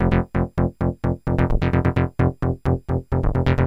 SONS ET LOOPS GRATUITS DE BASSES DANCE MUSIC 130bpm
Basse dance 1 E